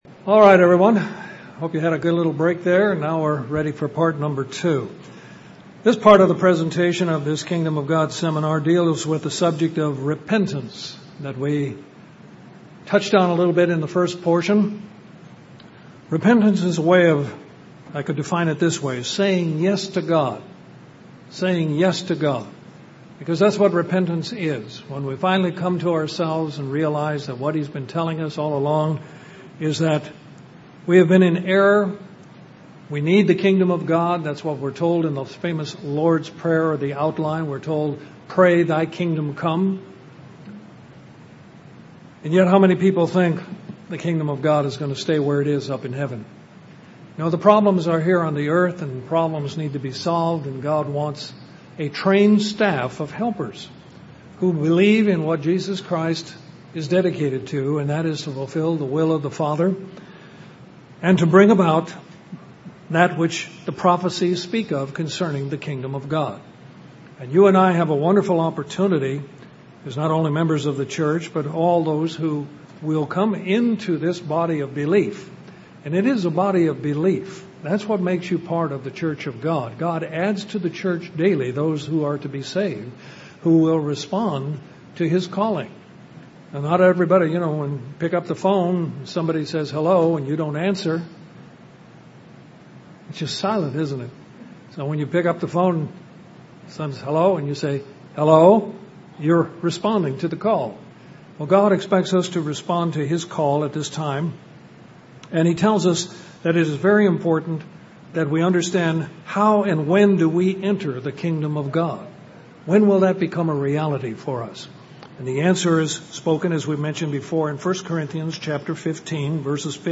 Given in Columbus, GA Central Georgia
UCG Sermon Studying the bible?